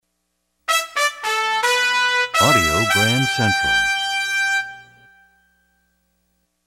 MCM Category: Radio Jingles